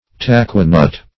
taqua-nut - definition of taqua-nut - synonyms, pronunciation, spelling from Free Dictionary Search Result for " taqua-nut" : The Collaborative International Dictionary of English v.0.48: Taqua-nut \Ta"qua-nut`\, n. (Bot.) A Central American name for the ivory nut.